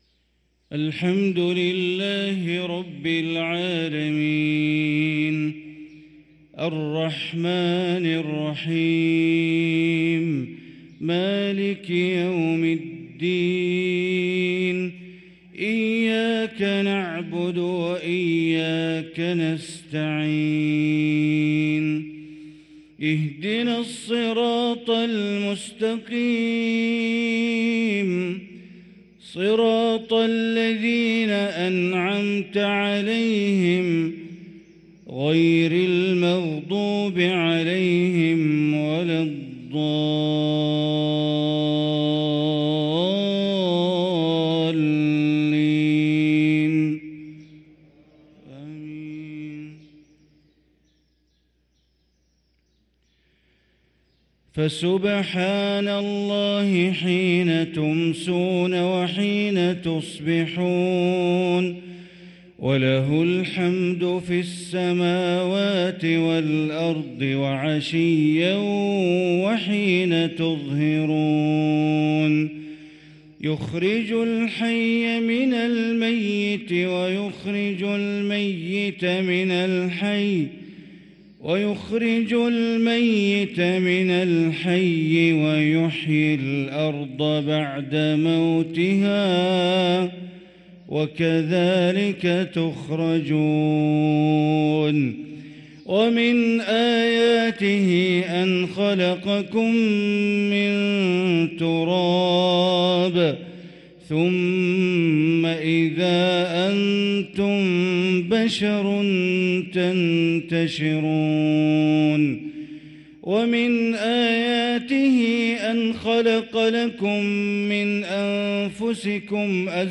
صلاة الفجر للقارئ بندر بليلة 1 جمادي الآخر 1445 هـ
تِلَاوَات الْحَرَمَيْن .